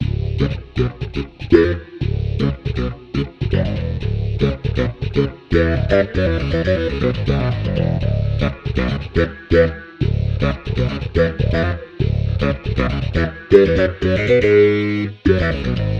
描述：第二次大规模freesound录音的外拍。
音频被剪切并归一化。
雄性 口语 语音 英语
声道立体声